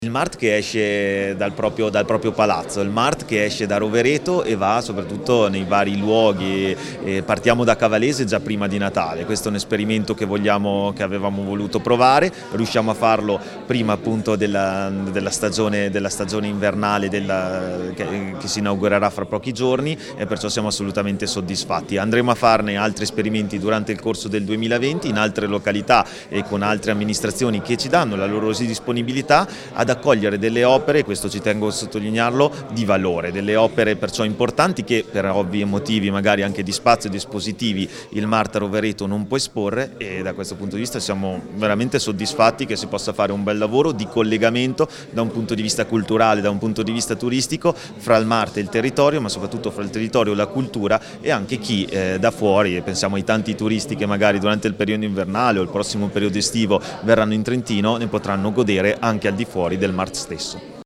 L'assessore alla cultura Mirko Bisesti è intervenuto all'incontro con la stampa per la presentazione a cura di Vittorio Sgarbi